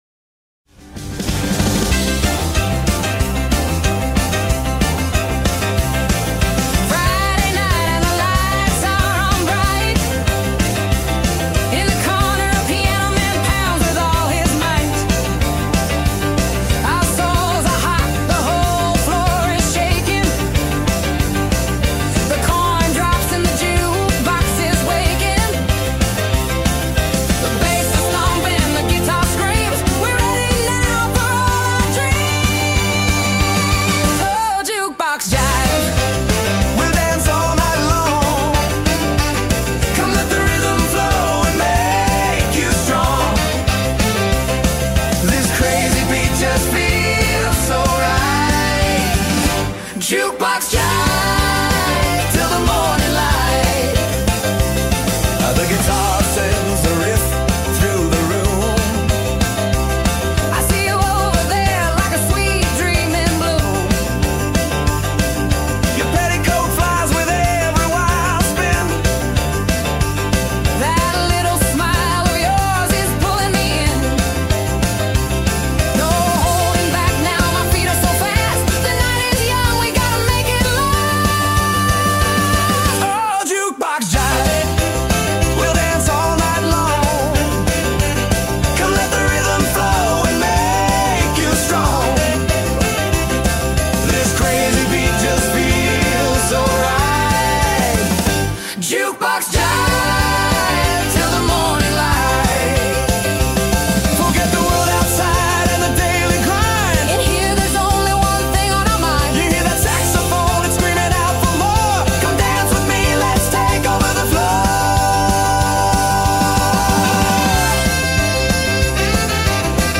artista de rock alemán
pop/rock